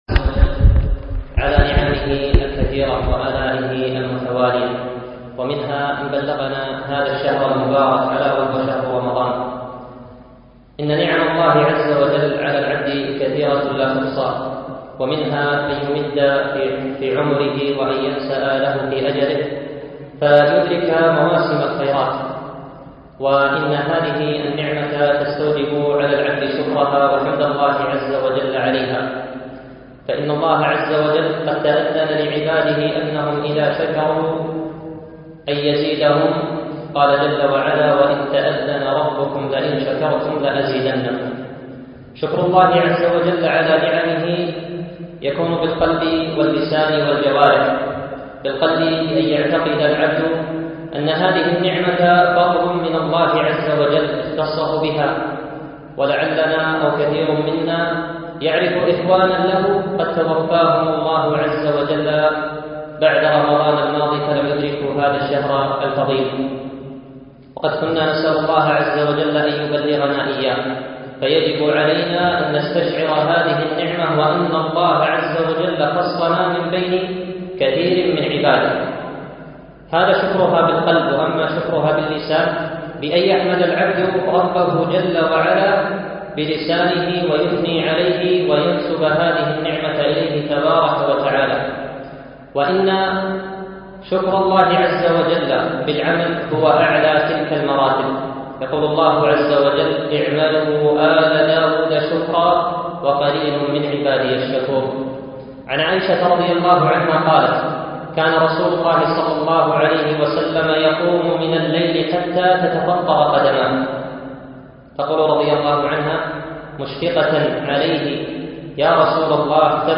يوم الأثنين 8 رمضان 1437 مسجد سعد بن عبادة خيطان